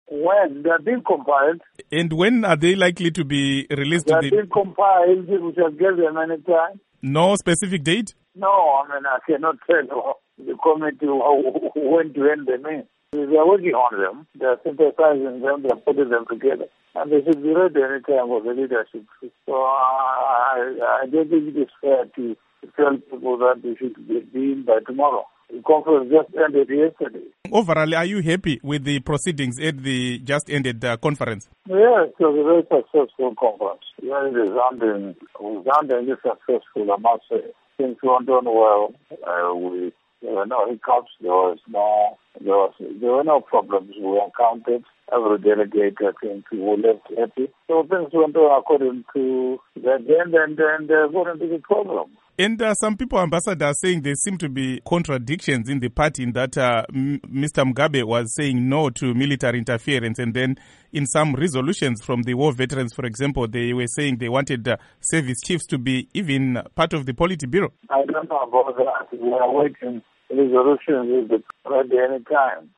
Interview With Simon Khaya Moyo Following The Zanu PF National Conference